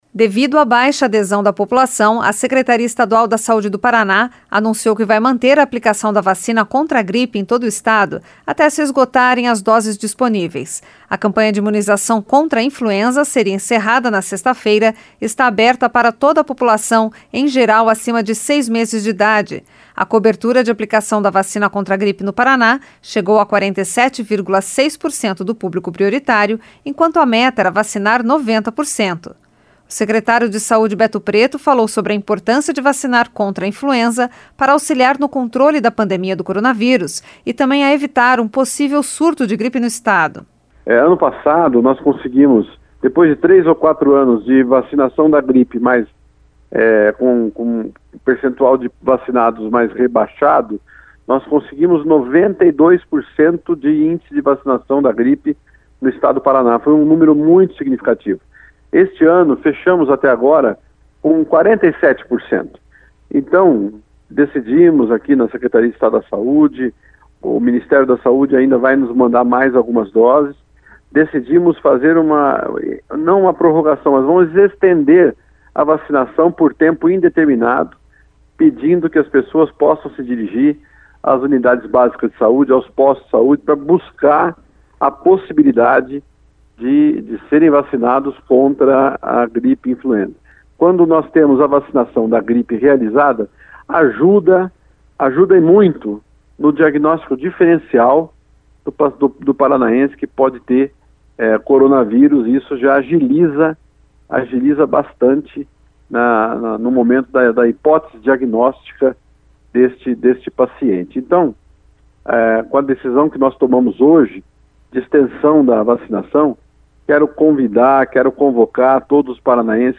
O secretário de Saúde Beto Preto falou sobre a importância de vacinar contra a Influenza para auxiliar no controle da pandemia do coronavírus e também a evitar um possível surto de gripe no Estado.